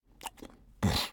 Minecraft Version Minecraft Version snapshot Latest Release | Latest Snapshot snapshot / assets / minecraft / sounds / mob / llama / idle5.ogg Compare With Compare With Latest Release | Latest Snapshot